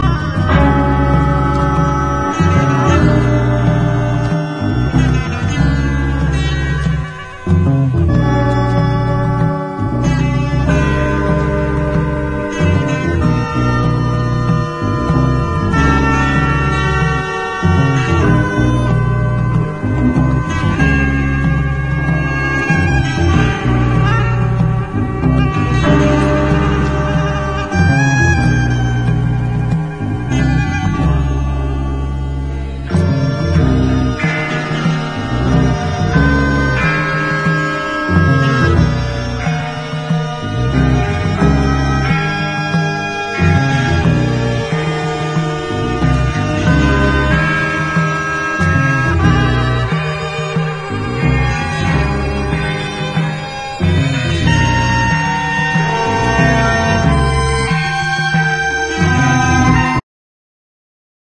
EASY LISTENING / VOCAL / PIANO